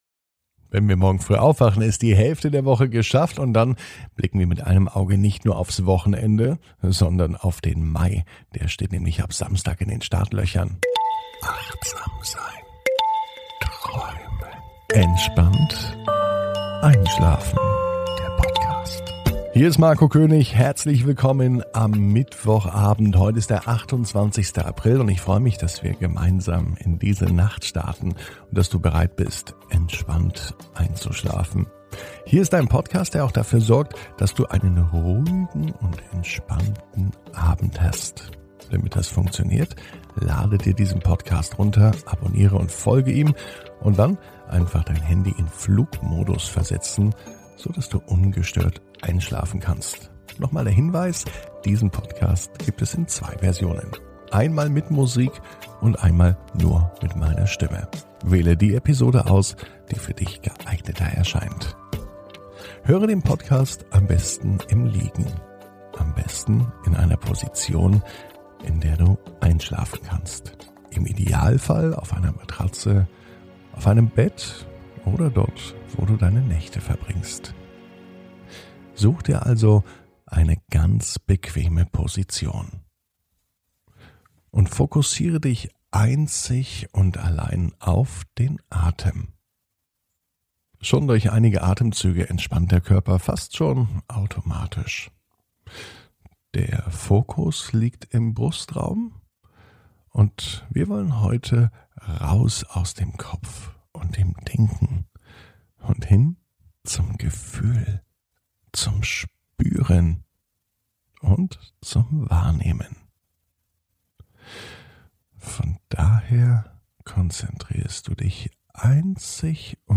(ohne Musik) Entspannt einschlafen am Mittwoch, 28.04.21 ~ Entspannt einschlafen - Meditation & Achtsamkeit für die Nacht Podcast